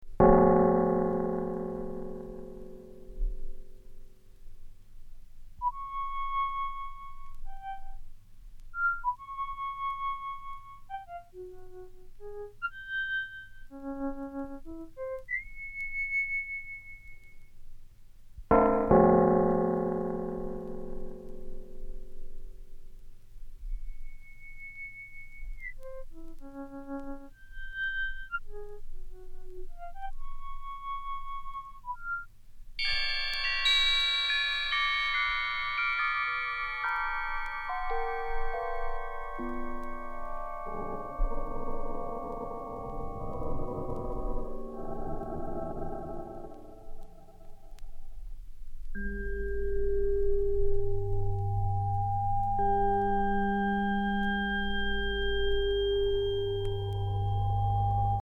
computer music
technology-based music
synthetic sounds